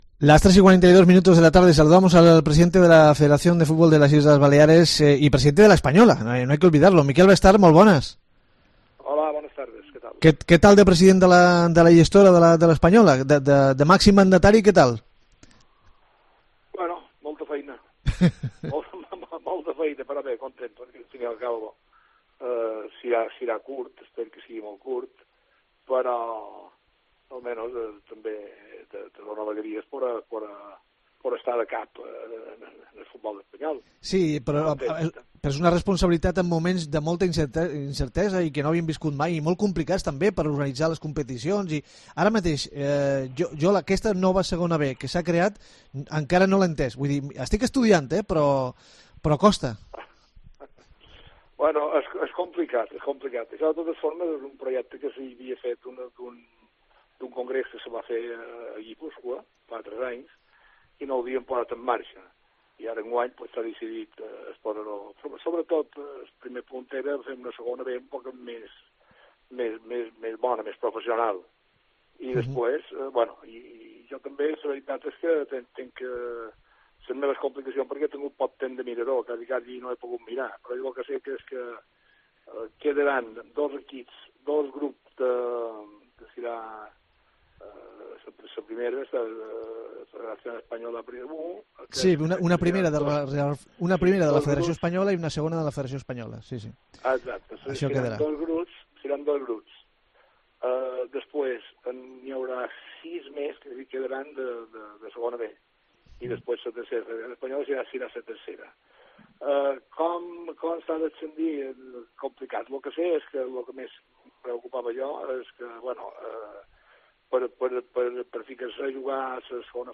No hay excusas afirma para no cumplir el protocolo que ya ha sido entregado, que se ha de cumplir a rajatabla afirma en la entrevista.